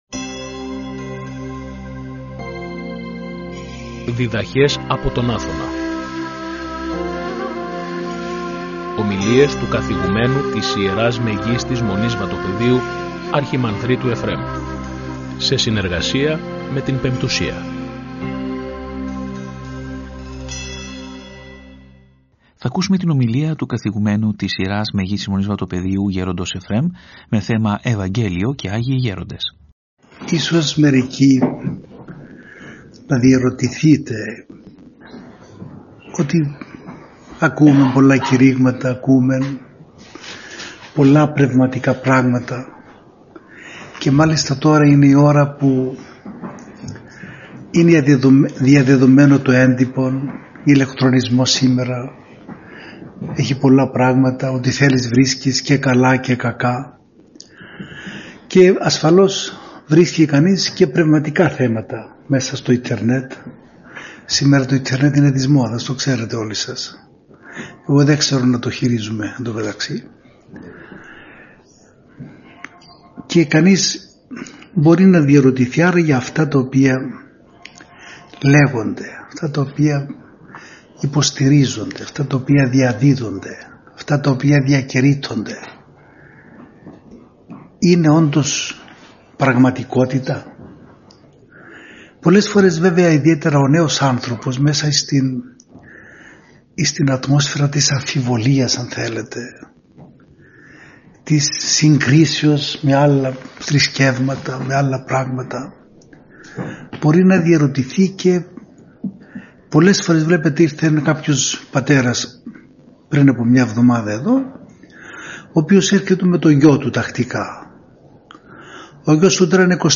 Ομιλία